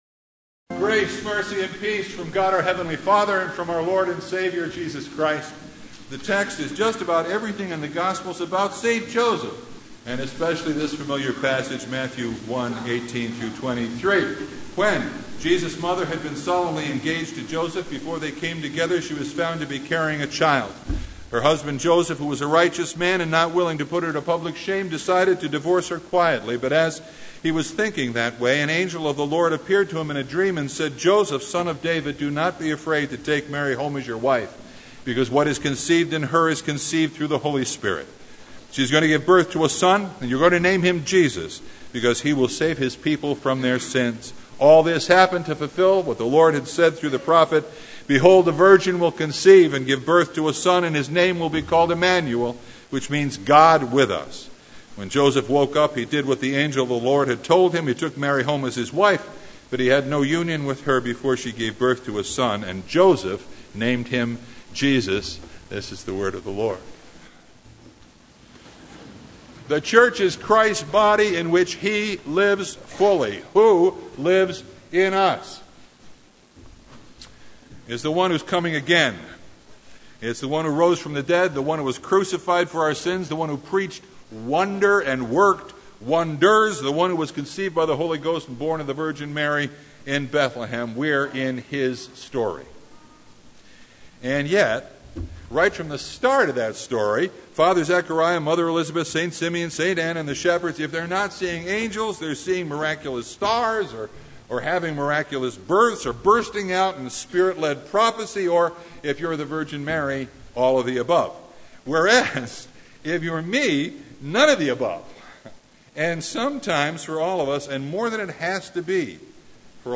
Passage: Matthew 1:18-23 Service Type: Sunday
Sermon Only